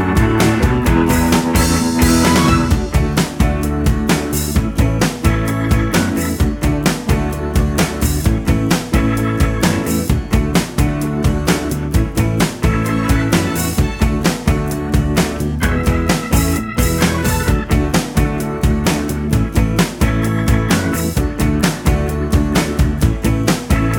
no Backing Vocals Easy Listening 3:16 Buy £1.50